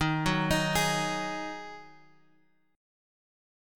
D#mbb5 chord {x 6 4 x 4 4} chord